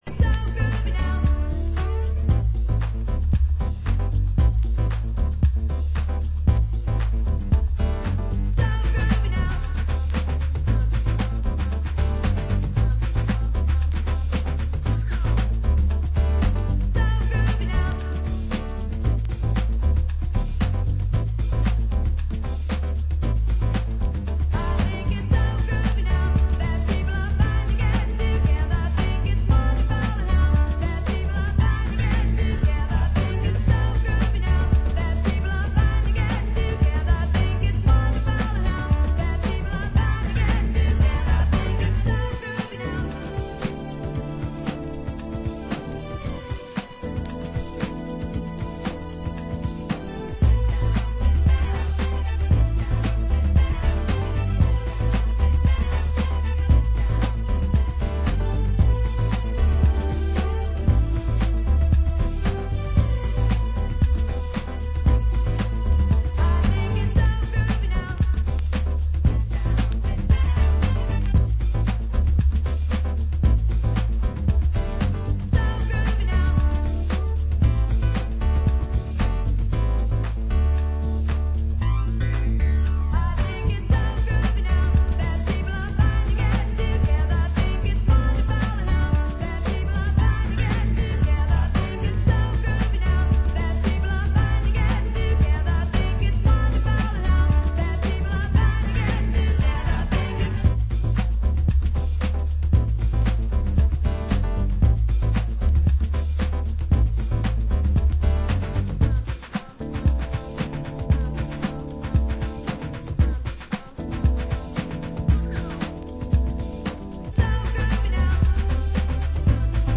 Genre: Acid Jazz